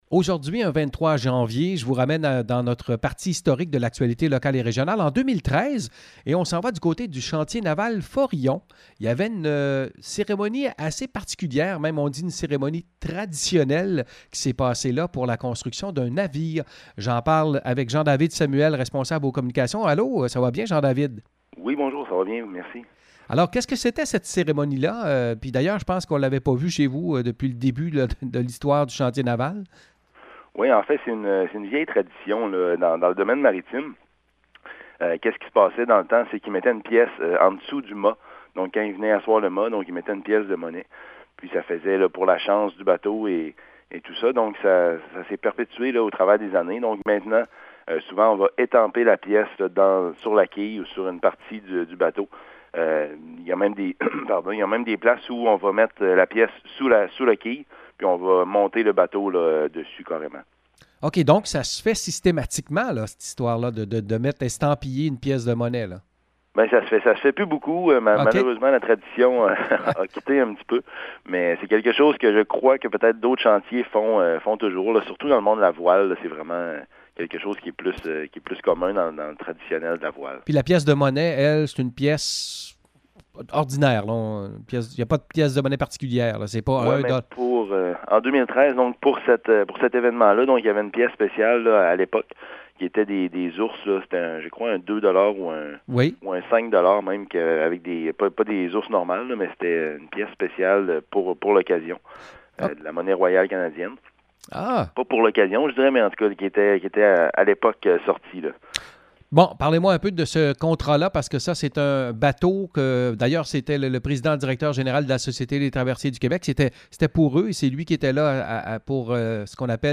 En 2013, avait lieu une cérémonie traditionnelle d’estampillage d’une pièce de monnaie sur un navire en construction au Chantier Navale Forillon. Écouter l’entrevue